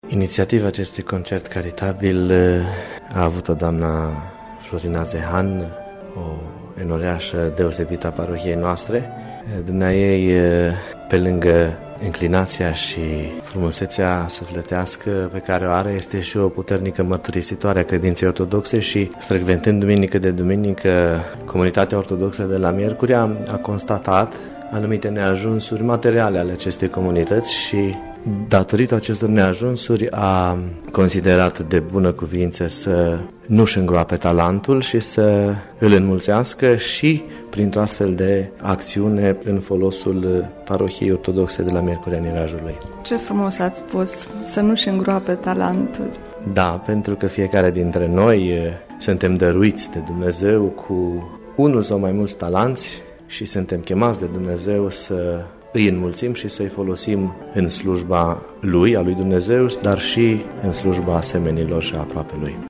Pentru fiecare minut de emoţie artistică, le-a mulţumit cu o rugăciune